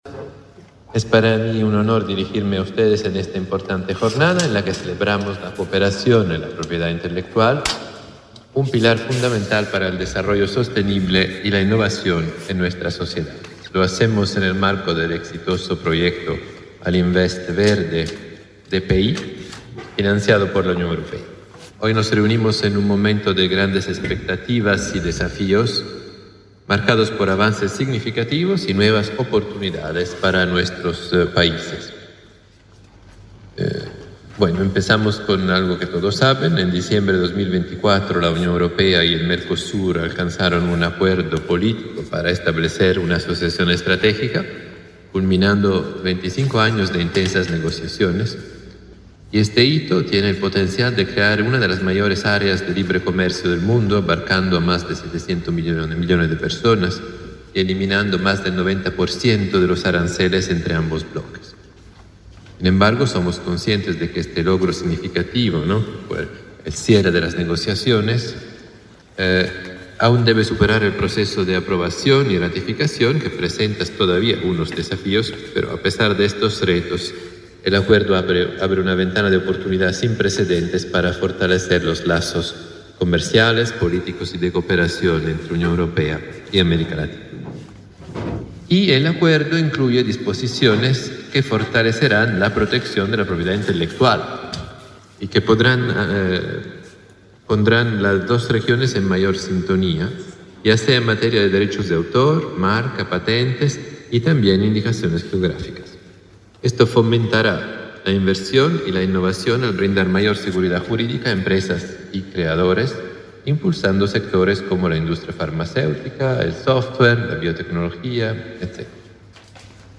Palabras de autoridades en acto del Ministerio de Industria
Este miércoles 19, en Montevideo, el embajador de la delegación de la Unión Europea en Uruguay, Paolo Berizzi, y la ministra de Industria, Elisa Facio